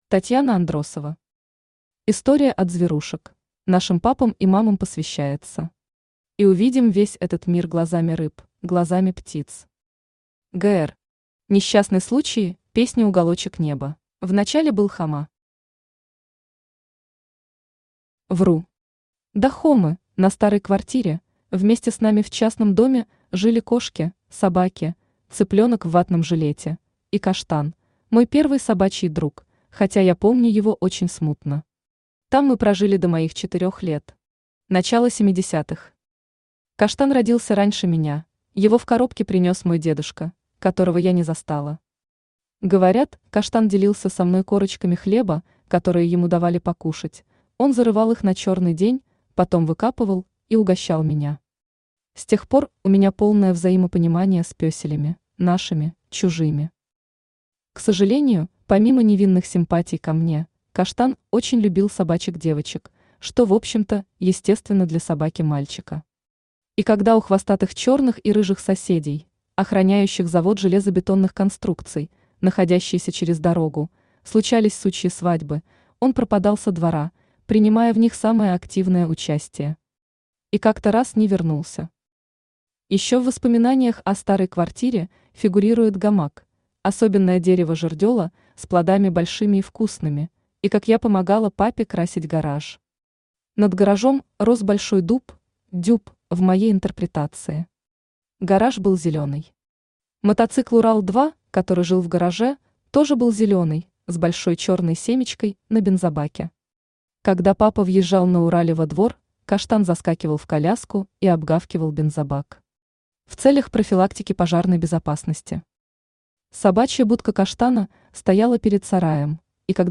Aудиокнига История от зверушек Автор Татьяна Андросова Читает аудиокнигу Авточтец ЛитРес.